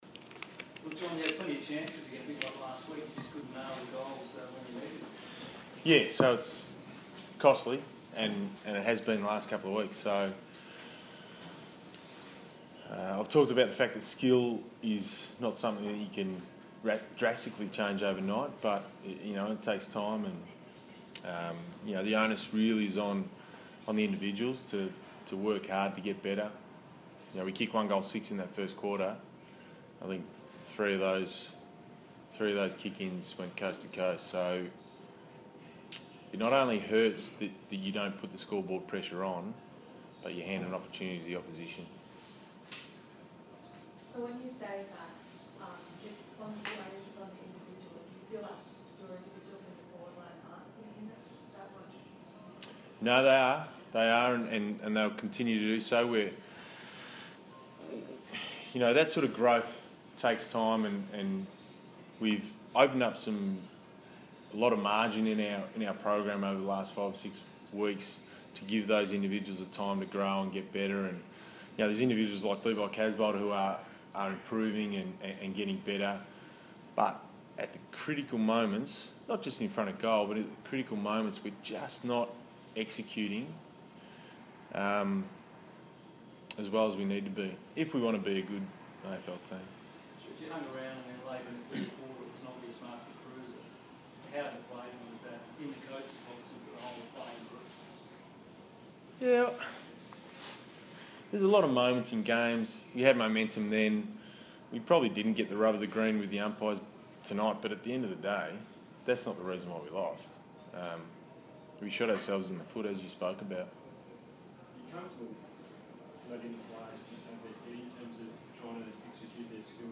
Round 15 post-match press conference